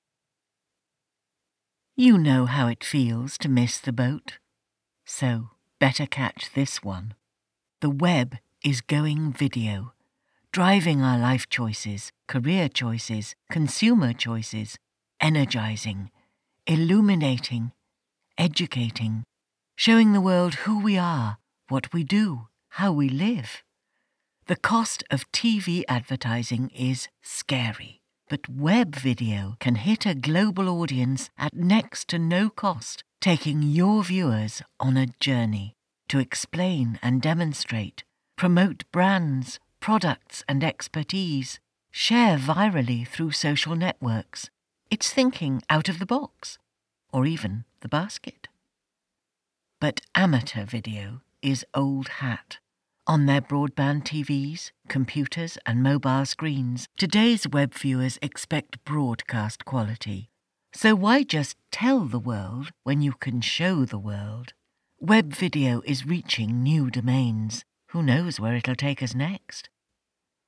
Corporate VO for Peak Time Productions
VO recorded for Peak Time Productions Promo film.